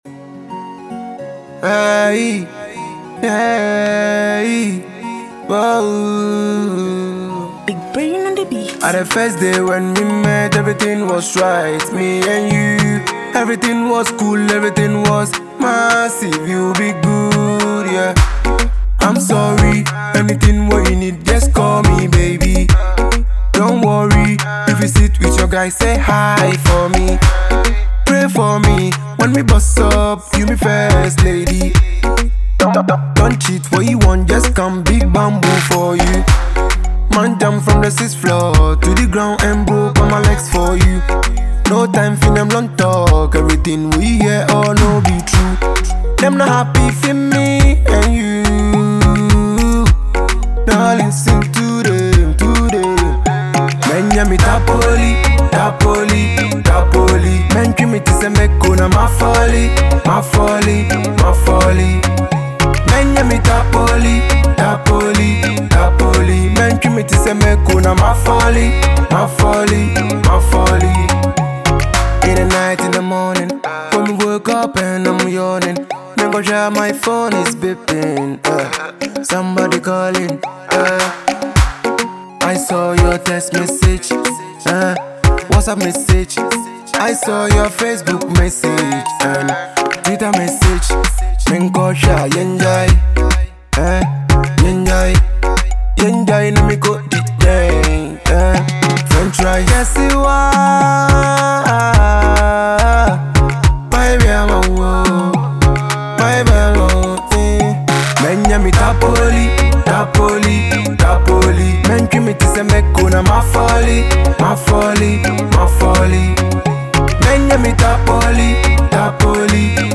hot love banger